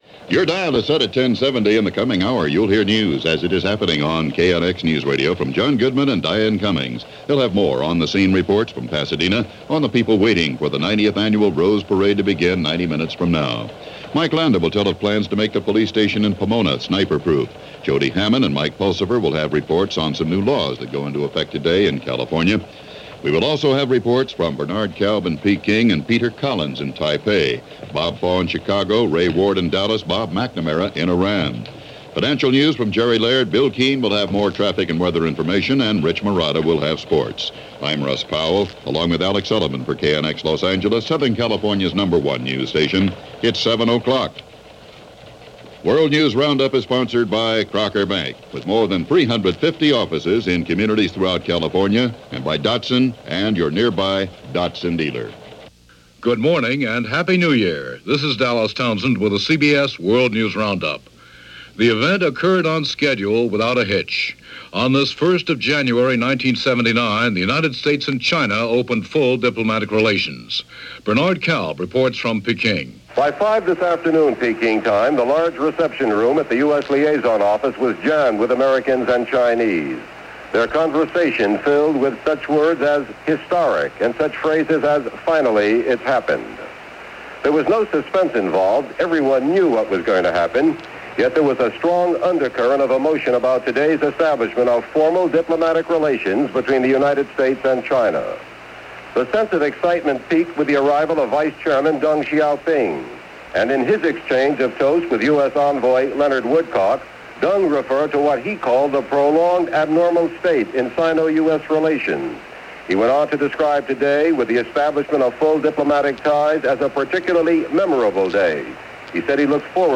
CBS World News Roundup + News from KNX-AM Los Angeles